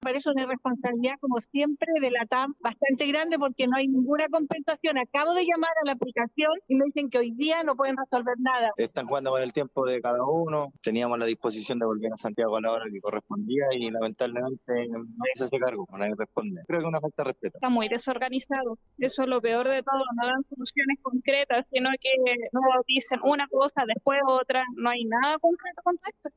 “Me parece una irresponsabilidad como siempre de Latam”, “están jugando con el tiempo de cada uno”, “una falta de respeto” y “muy desorganizado”, declararon algunos pasajeros.